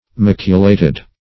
Maculated \Mac"u*la`ted\